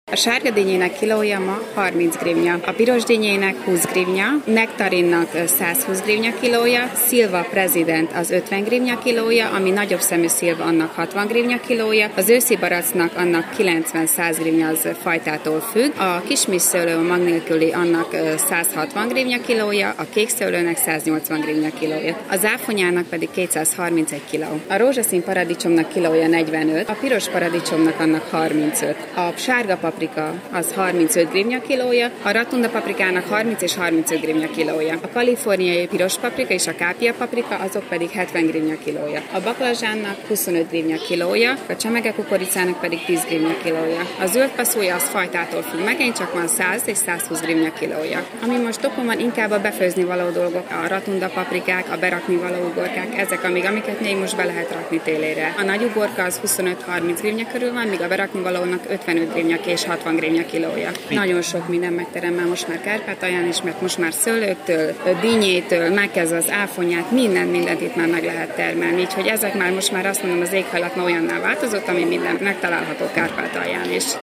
zoldseg_riport.mp3